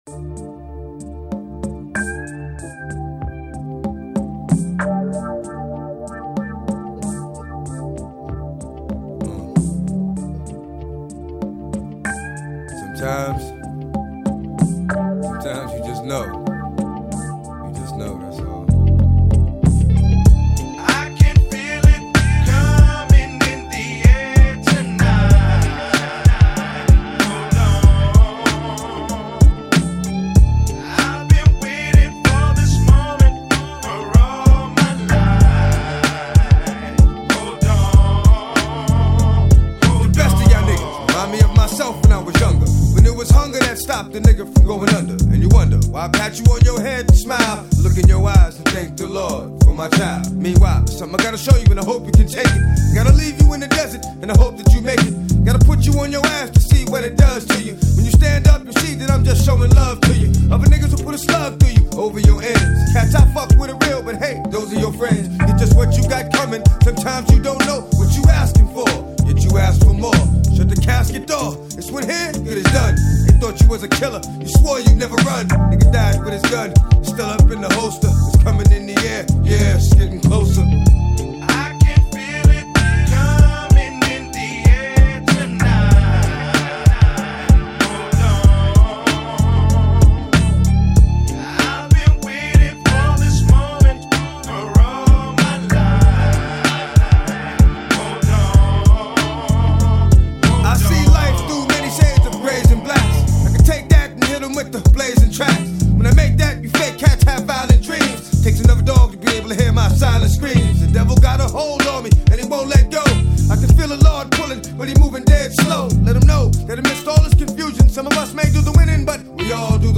Жанр: Rap
Рэп Хип-хоп.